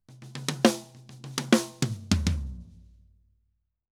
Drum_Break 100_3.wav